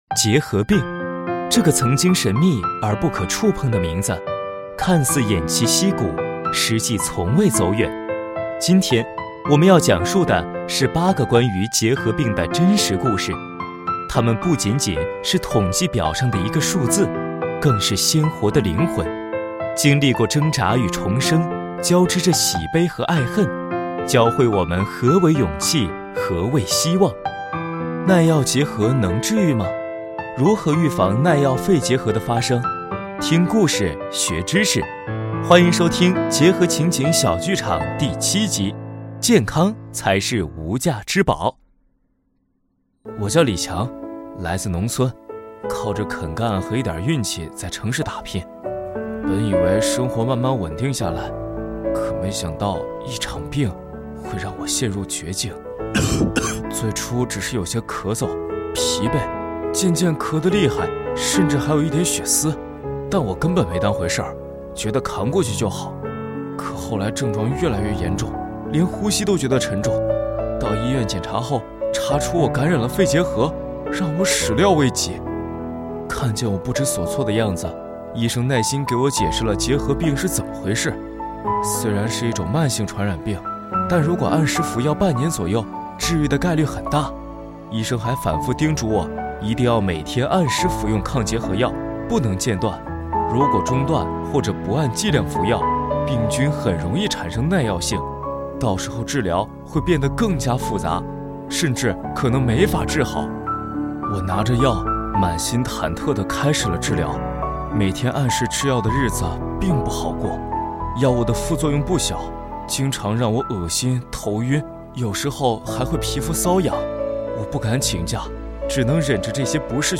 耐药结核能治愈吗？如何预防耐药肺结核的发生？听故事，学知识，欢迎收听结核情景小剧场第七集《健康才是无价之宝》。